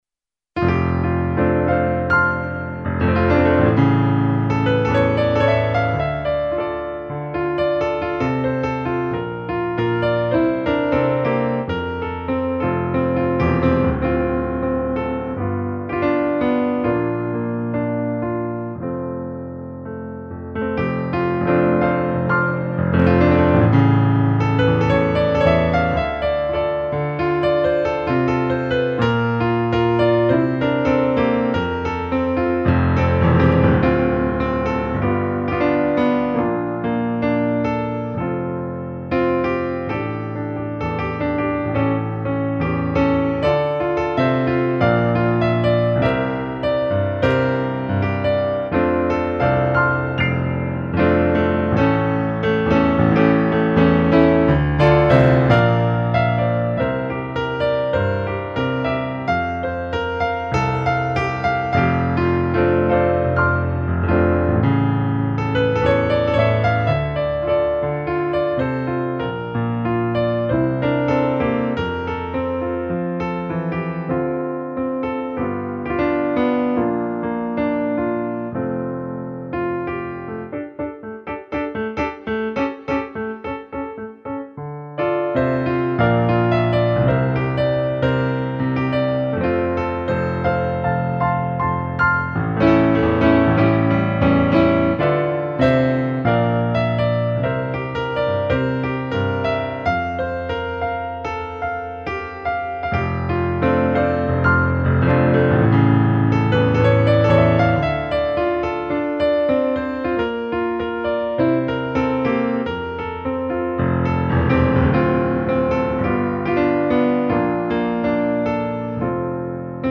Just piano (no vocals) sample tracks